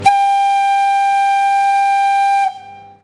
Шум паровозного гудка